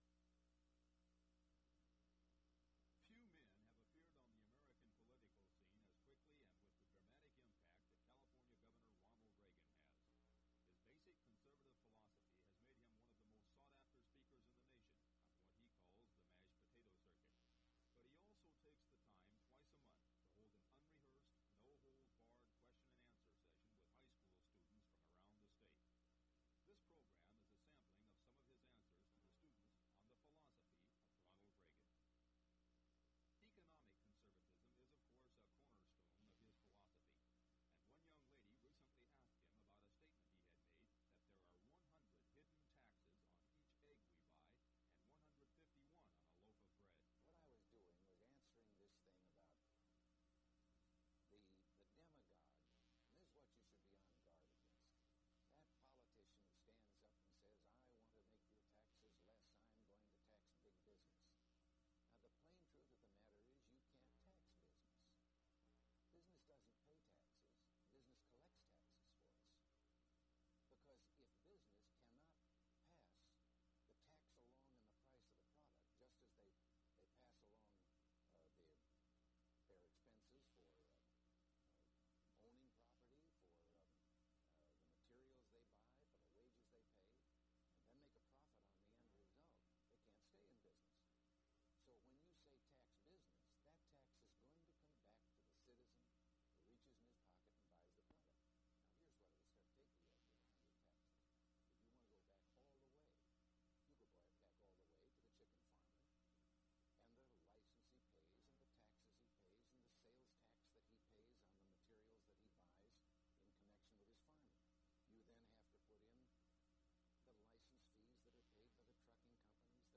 Reel to Reel Audio Format. 1967-74.